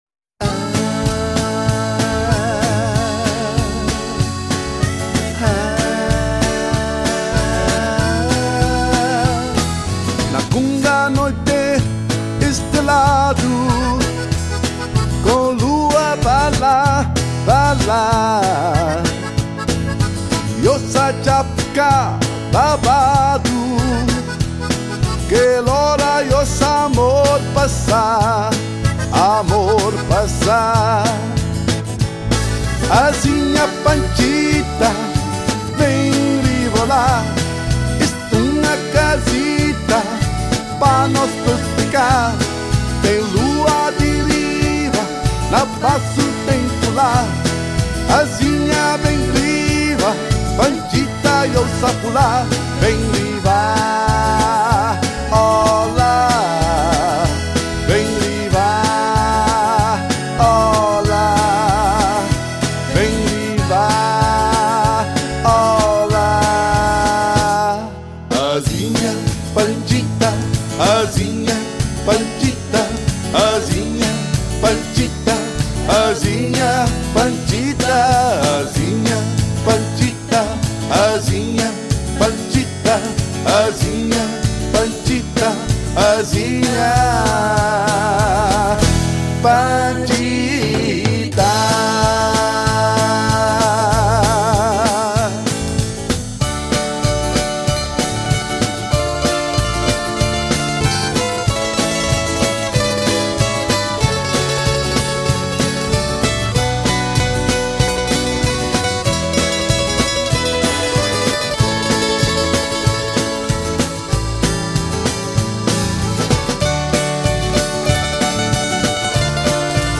（土生葡人歌曲）